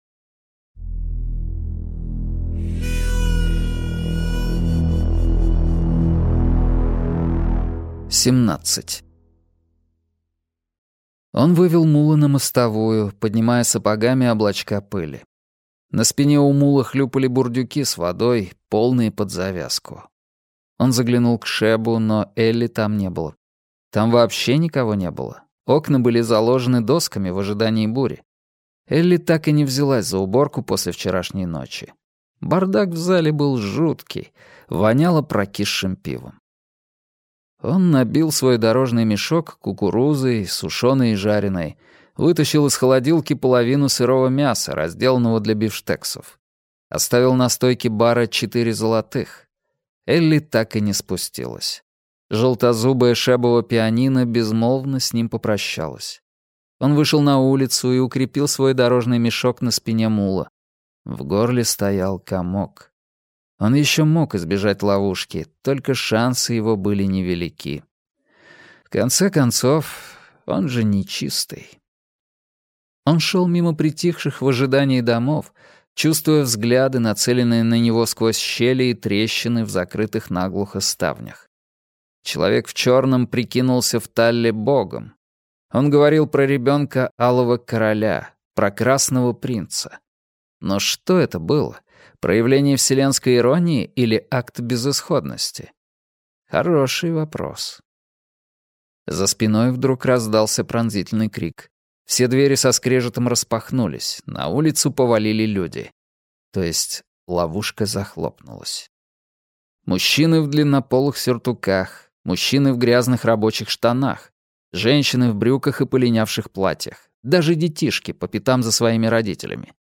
Аудиокнига Стрелок - купить, скачать и слушать онлайн | КнигоПоиск